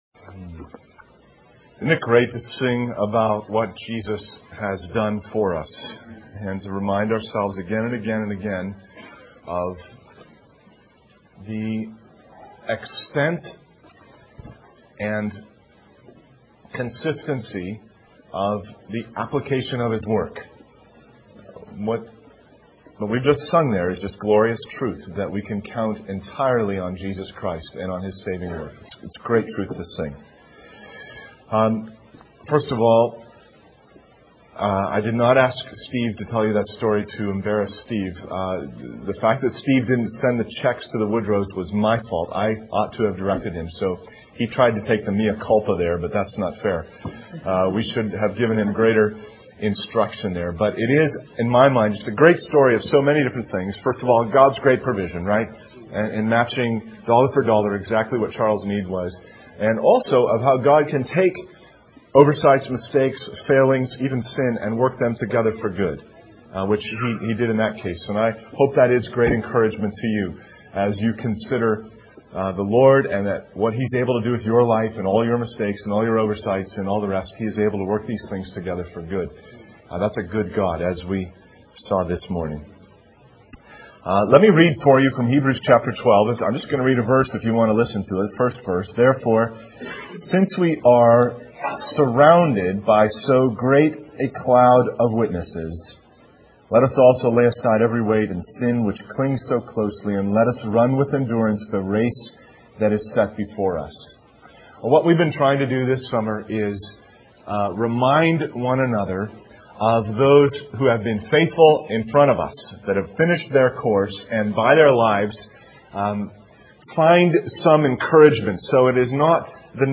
In this sermon, the speaker discusses the life of Robert Murray McShane, a man who pursued God with all his strength and found Him.